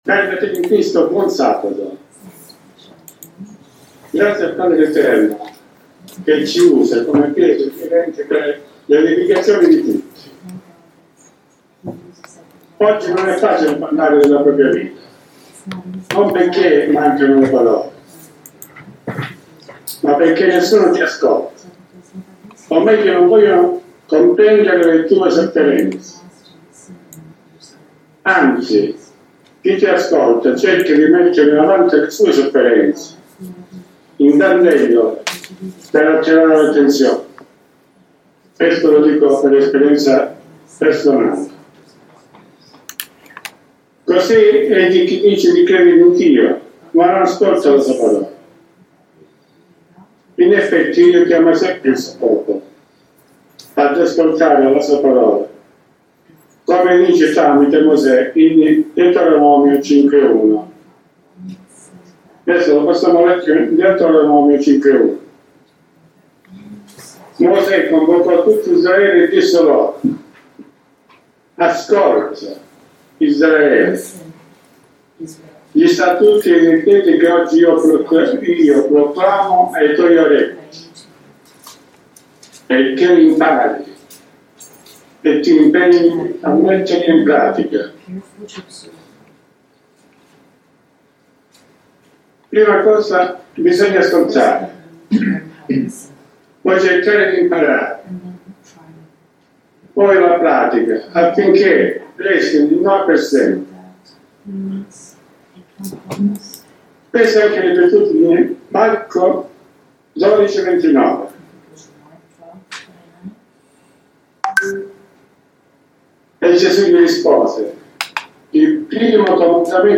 Testimonianza